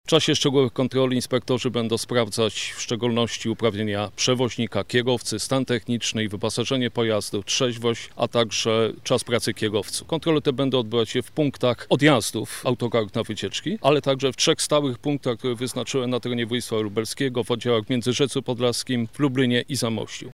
O tym, co jest weryfikowane podczas takiej kontroli, mówi lubelski wojewódzki inspektor transportu drogowego Piotr Winiarski: